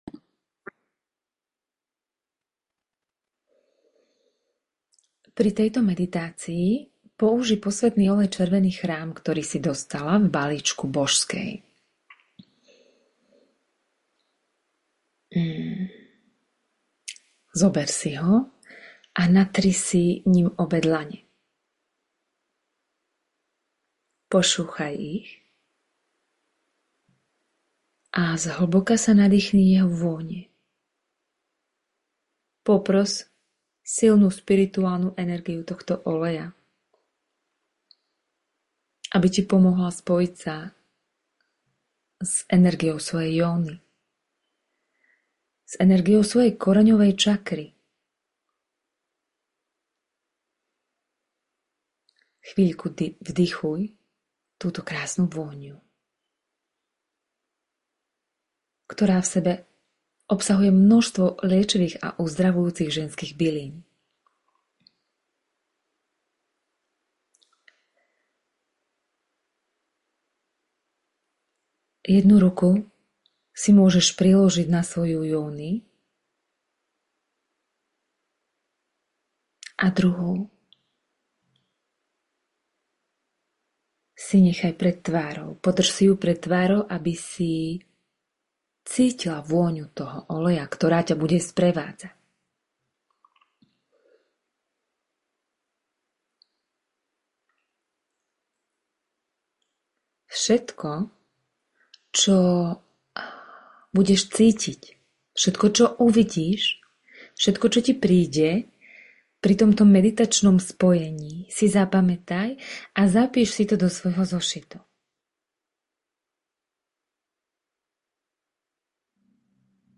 Meditácia - SPOJENIE S YONI 3.